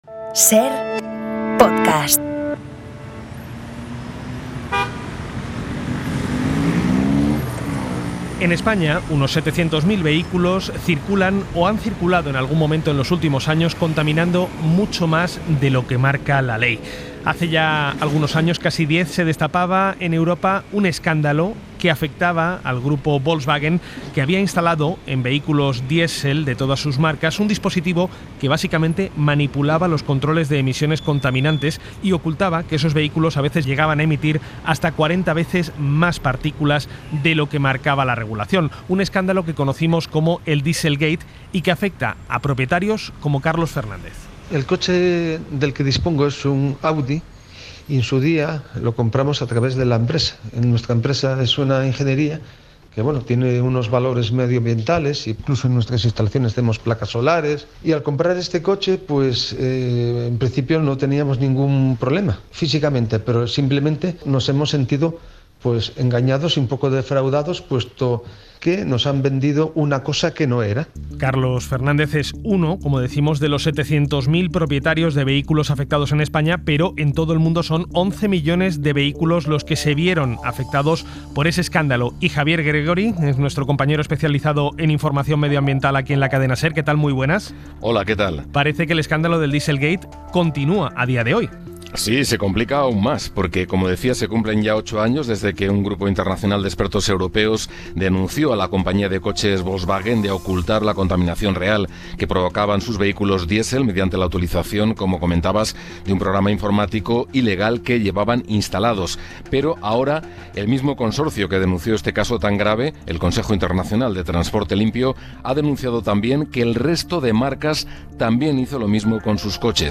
Un abogado especialista nos explica las consecuencias de la sentencia del Tribunal de Justicia de la UE que reconoce a los afectados por el escándalo del dieselgate el derecho a una indemnización. Y conocemos los detalles de la normativa comunitaria que prohíbe la venta de nuevos vehículos de combustión a partir de 2035.